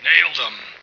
flak_m/sounds/male1/int/M1nailedem.ogg at fd5b31b2b29cdd8950cf78f0e8ab036fb75330ca